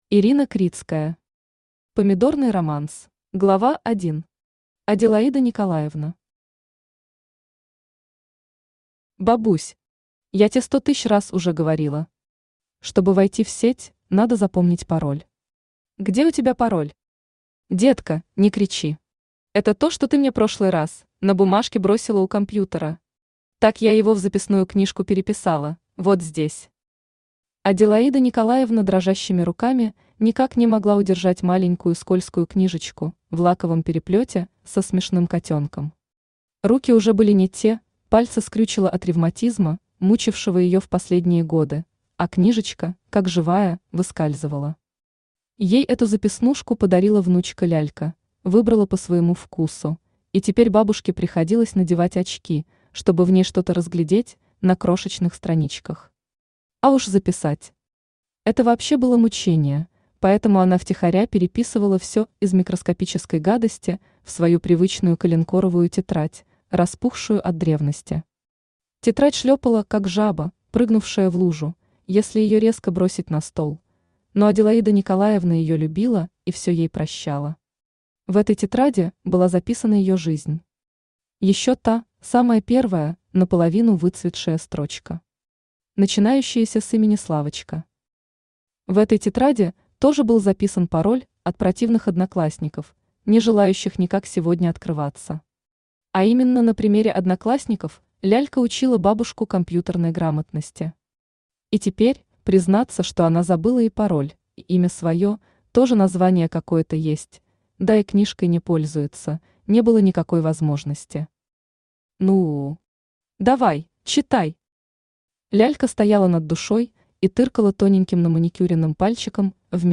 Аудиокнига Помидорный романс | Библиотека аудиокниг
Aудиокнига Помидорный романс Автор Ирина Критская Читает аудиокнигу Авточтец ЛитРес.